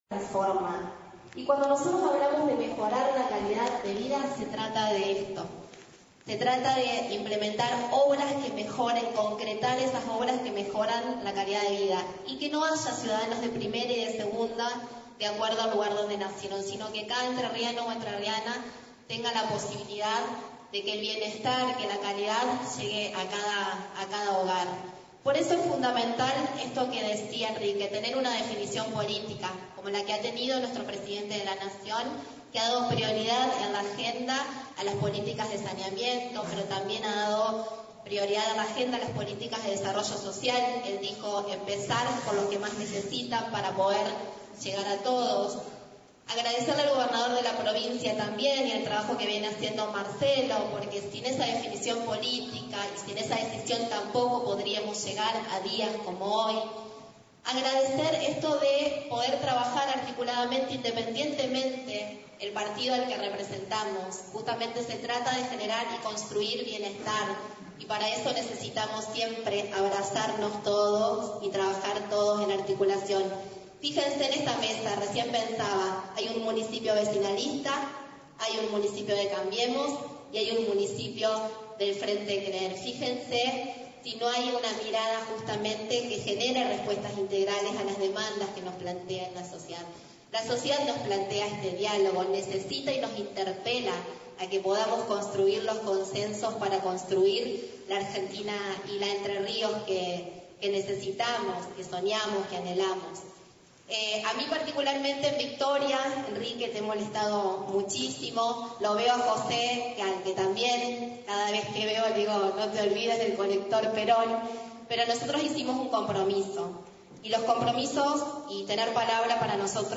Palabras de la vicegobernadora María Laura Stratta “nosotros hicimos un compromiso, por eso hoy estamos para financiar la obra del colector cloacal”